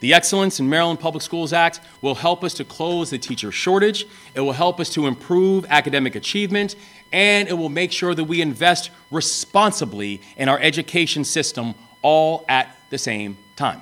Maryland Governor Wes Moore signed the Excellence in Maryland Public Schools Act into law Tuesday in Annapolis. The legislation trims the original Blueprint for Education and Governor Moore said that several goals have been reached with the new law…